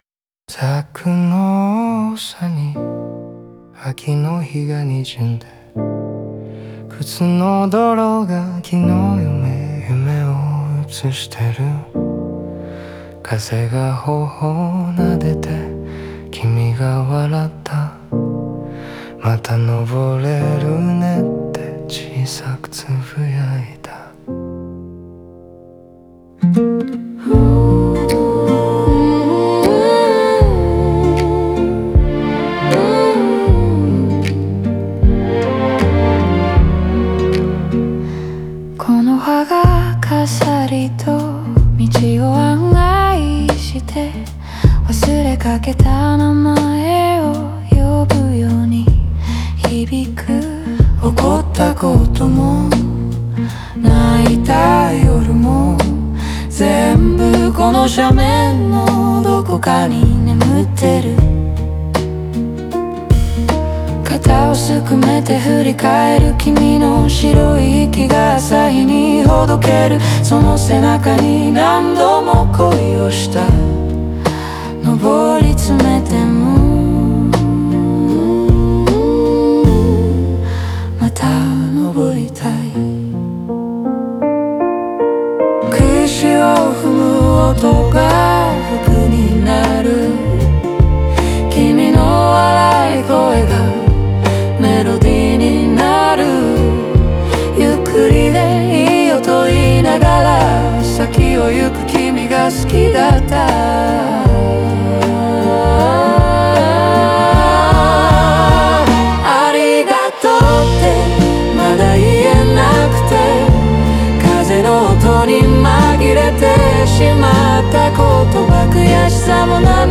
静かで力強い再生のうた。